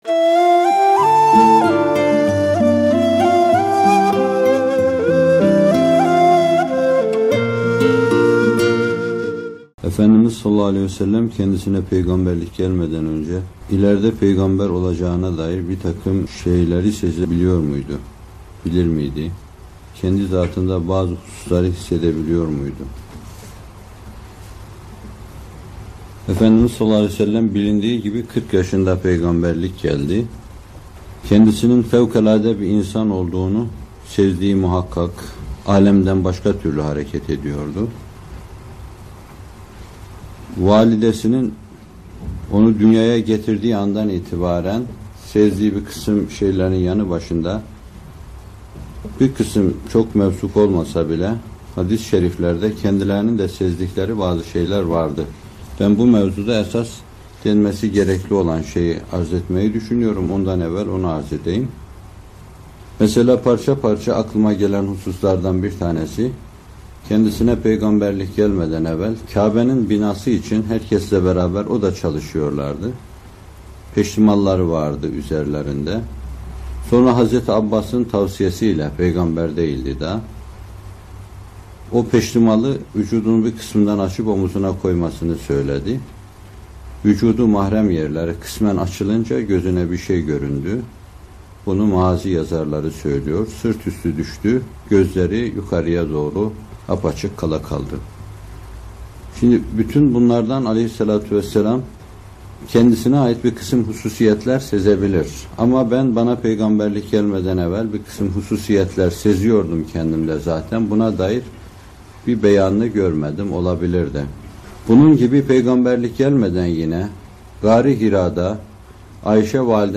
Muhterem Fethullah Gülen Hocaefendi bu videoda Şûrâ Suresi 52. ayet-i kerimesinin tefsirini yapıyor: